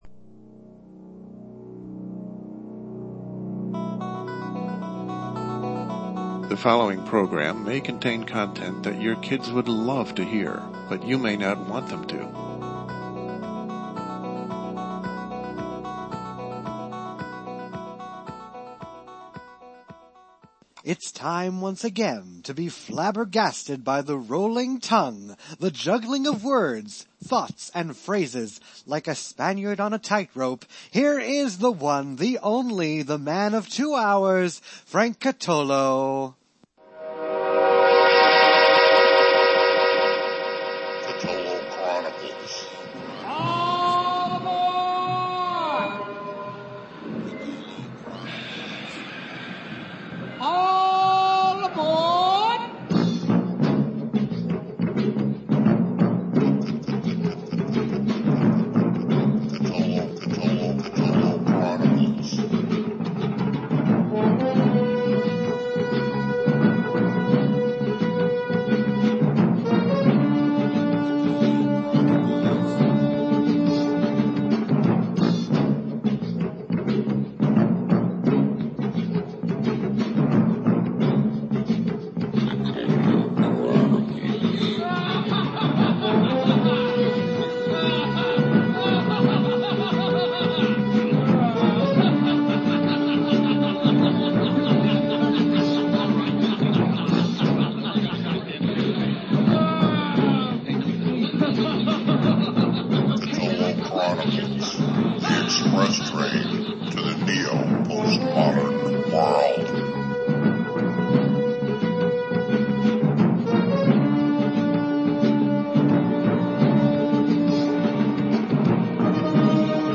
LIVE, Thursday, Sept. 13 at 9 p.m. LIVE, LIVE AGAIN !